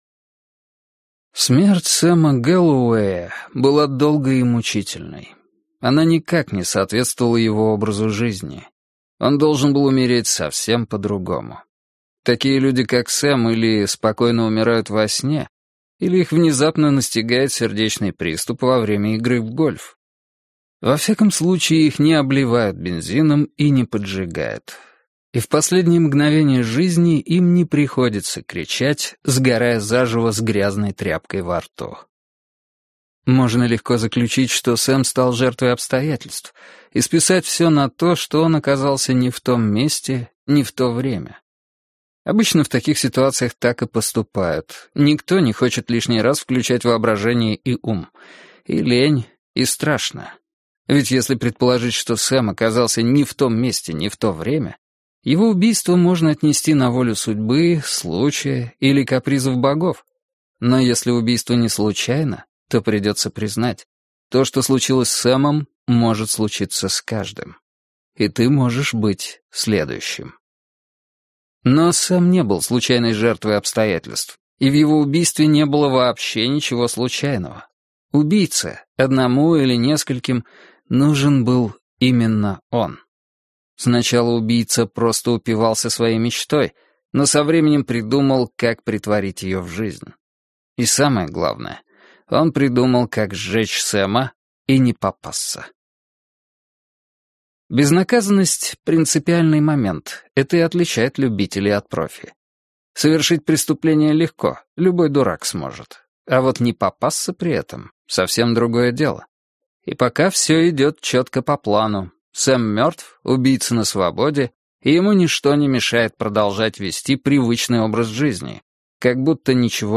Аудиокнига Смотри на меня - купить, скачать и слушать онлайн | КнигоПоиск